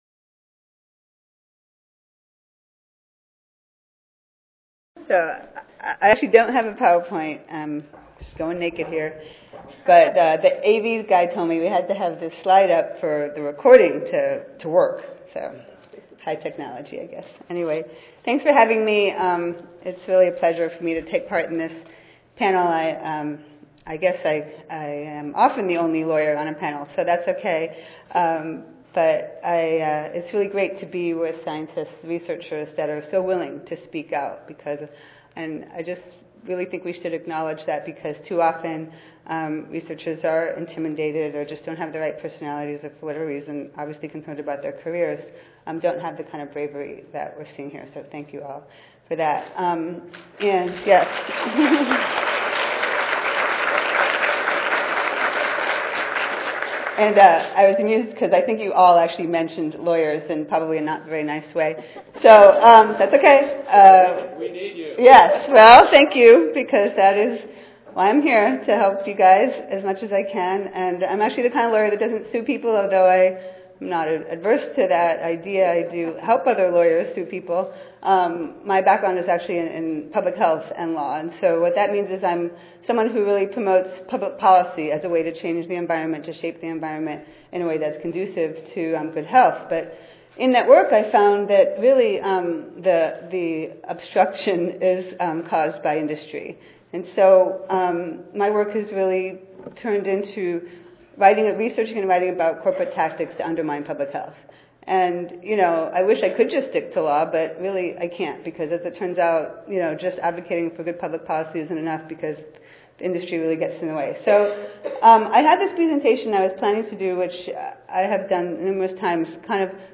This presentation describes who industry groups are, how they function, and how to best respond to their tactics. The goal is to help public health advocates be more effective in countering industry opposition.